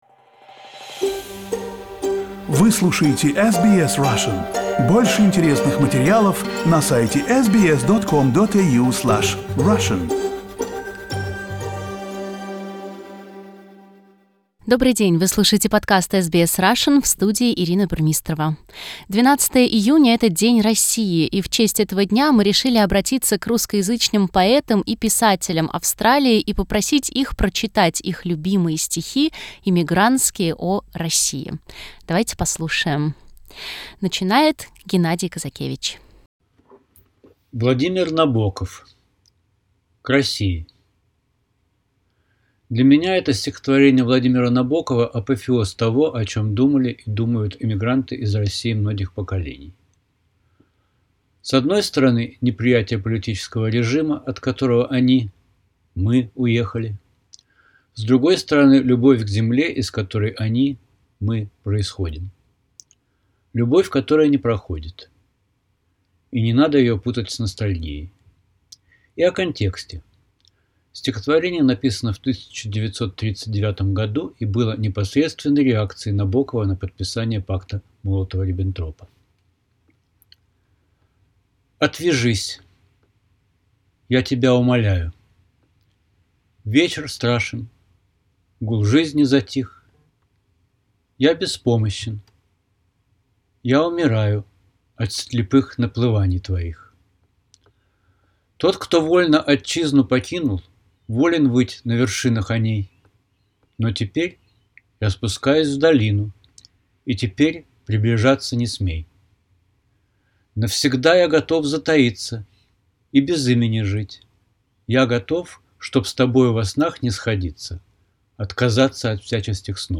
Poets and writers read their favourite emigrant's poems